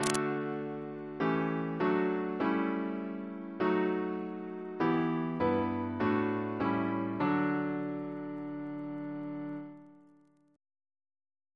CCP: Chant sampler
Single chant in D Composer: William V. Wallace (1812-1865) Reference psalters: PP/SNCB: 91